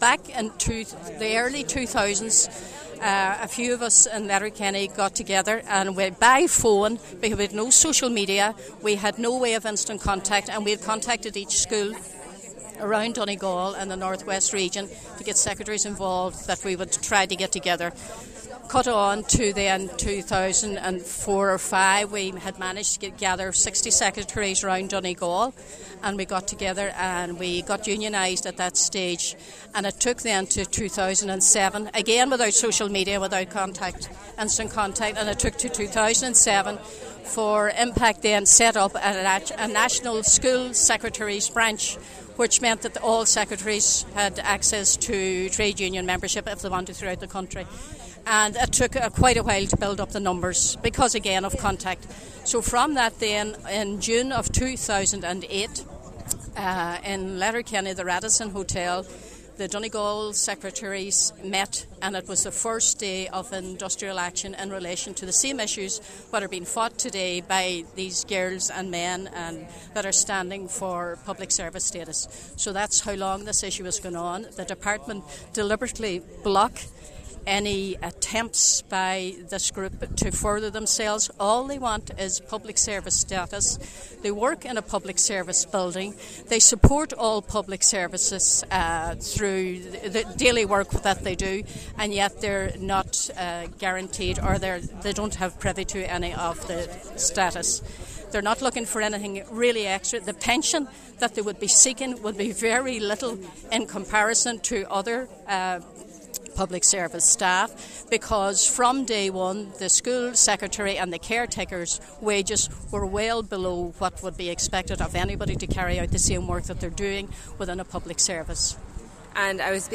Highland Radio News spoke to those standing out in protest, and three recurring themes came into conversation: guilt, fear, and anger.